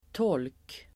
Uttal: [tål:k]